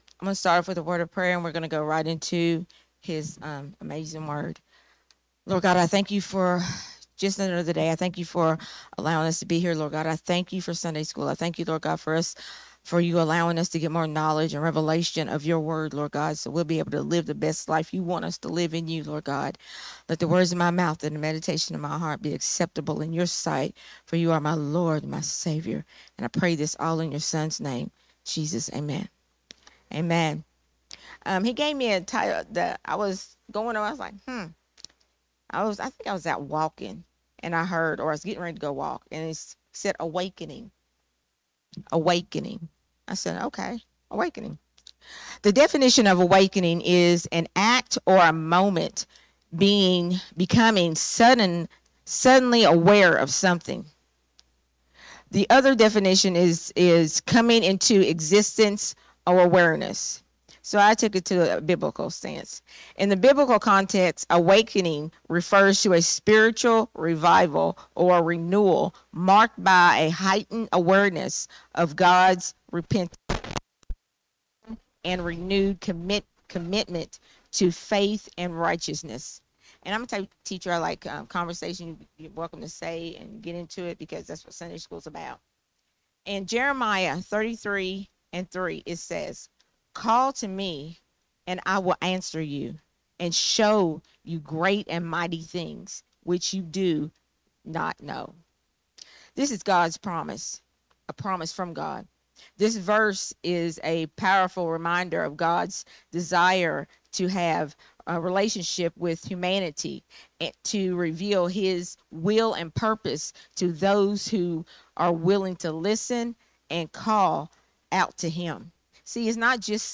Sunday Morning Risen Life teaching